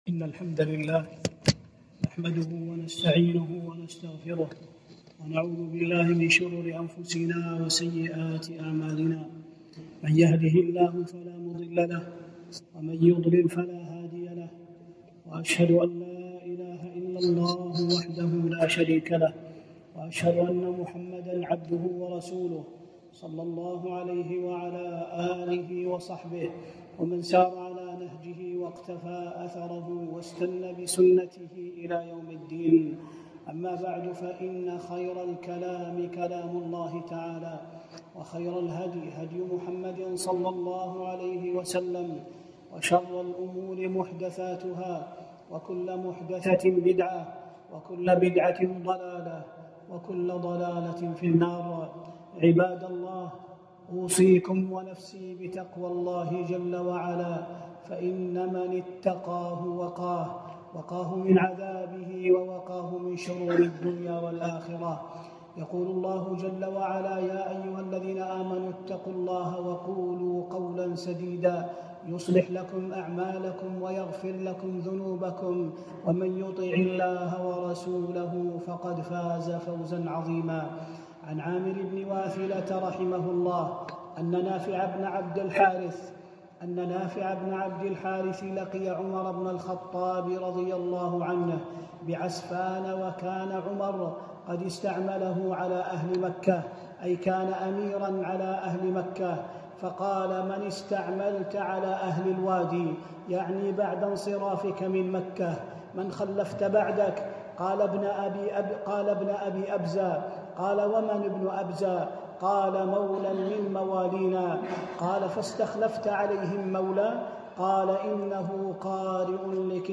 خطبة - فضل قراءة القرآن